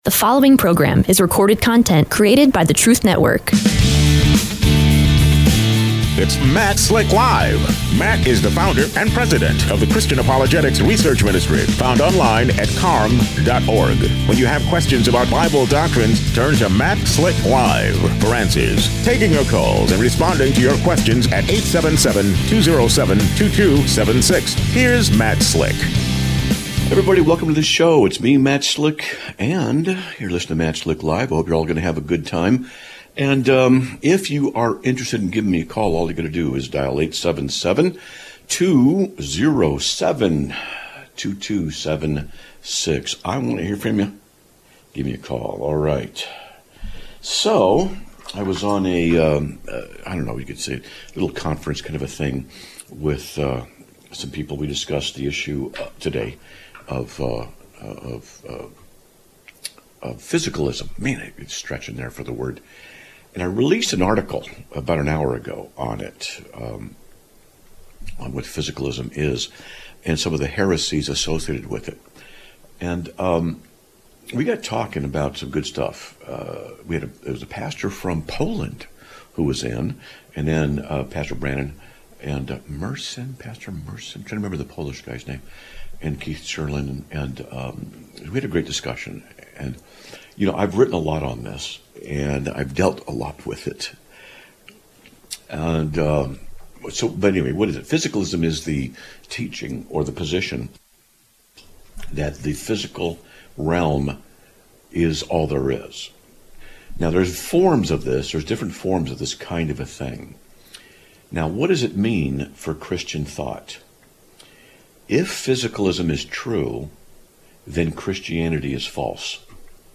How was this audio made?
Live Broadcast of 01/30/2026